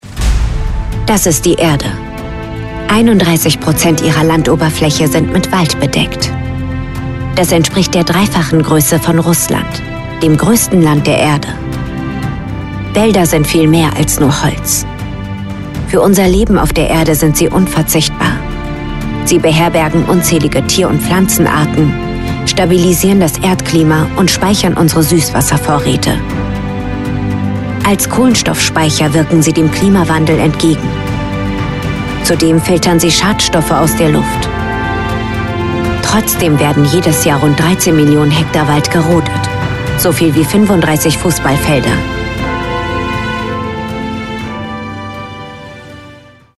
Was macht ein Sprecher für Dokumentationen?
Die Vertonung erfolgt in einem unserer vier hauseigenen Tonstudios, die professionell eingerichtet und ausgestattet sind.